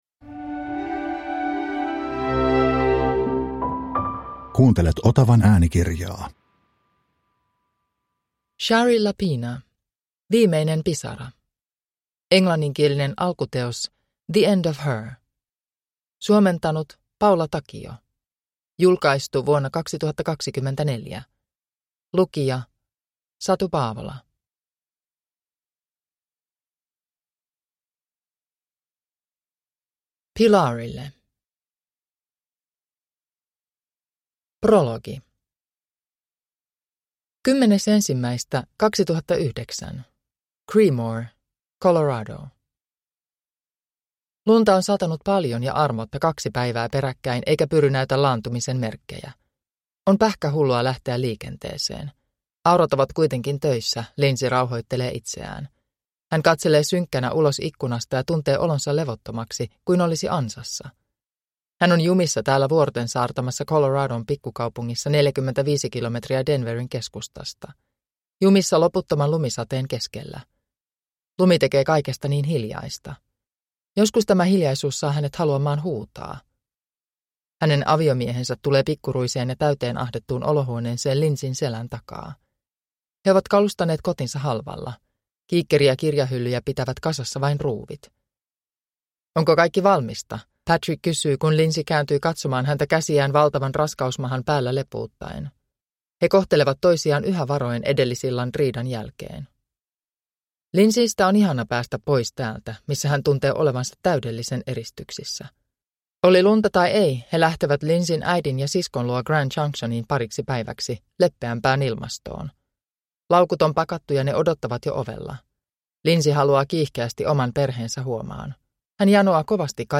Viimeinen pisara (ljudbok) av Shari Lapena